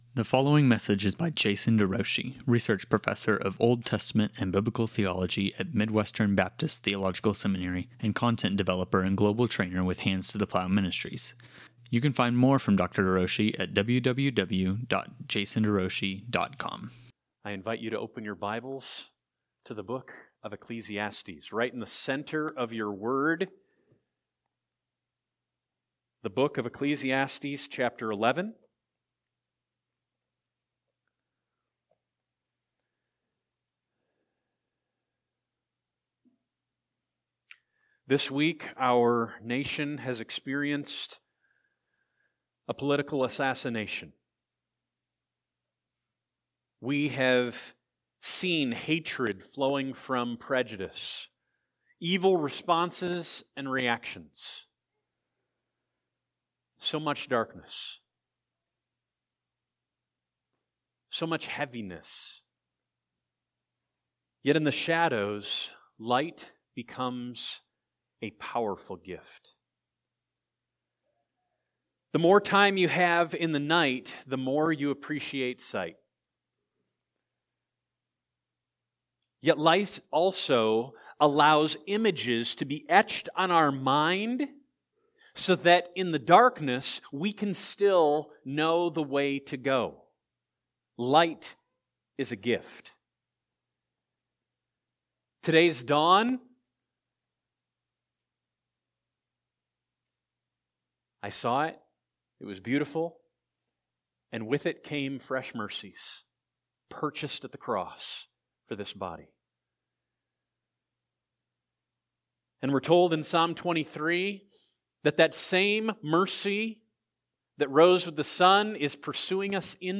Rejoice in the Lord Always: A Sermon on Ecclesiastes 11:7–12:1